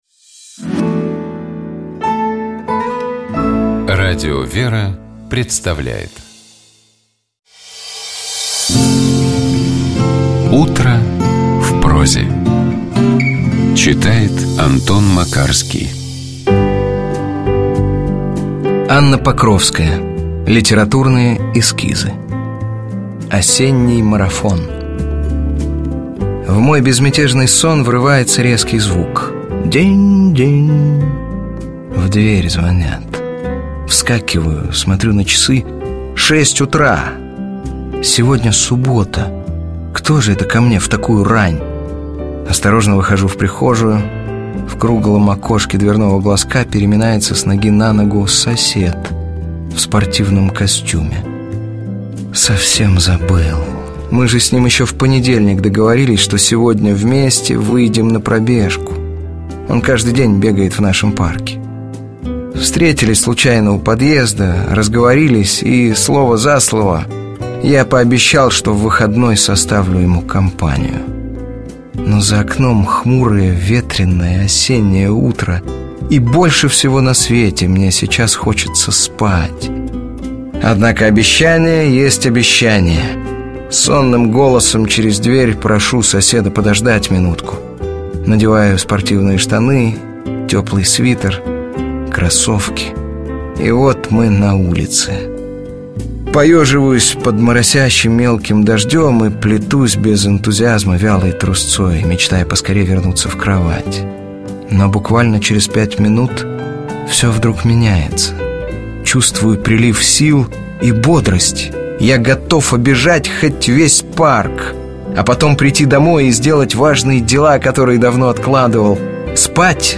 Текст Анны Покровской читает Антон Макарский.